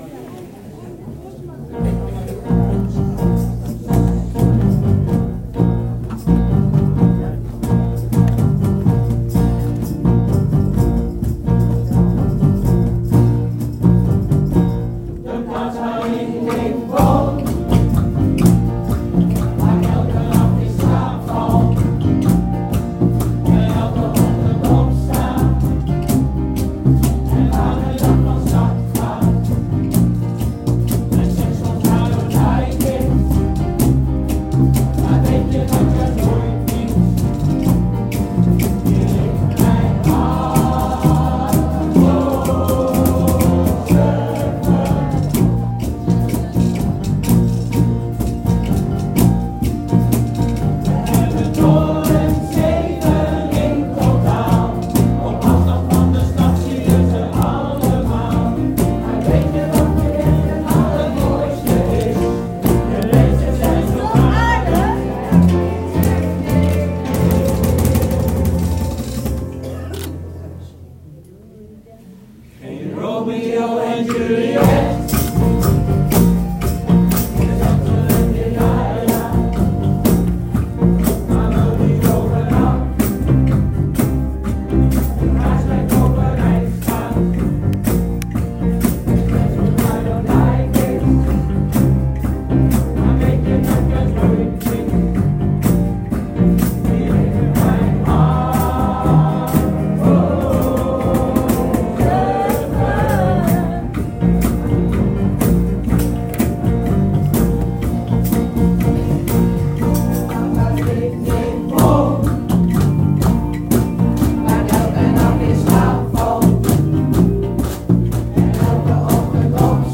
↑ Onbewerkte opname van de nummers ›Fire‹ (›Zutphen!
De BuurtBands van Zutphen en Brummen traden zaterdag 18 januari gezamenlijk op in zaal Plein Vijf in Brummen.
The Battle Of The BuurtBands eindigde onbeslist en was natuurlijk in wezen juist helemaal geen Battle, want even later, na wat vrolijke intermezzo-geluiden (altijd leuk/interessant om die óók op te nemen) gingen ze samen vol overgave verder in een welgemeend-aanstekelijk ›Happy Together‹ van The Turtles, eveneens in een speciale BuurtBand-uitvoering.
Zelfgebouwde instrumenten (van regenpijpen!) liggen klaar en klinken verrassend goed. Zowel in Zutphen als Brummen doen bandleden mee die al wèl ervaring hebben op muziekgebied en hun gitaar, accordeon of dwarsfluit meebrengen.
Drie blazers kwamen de bands versterken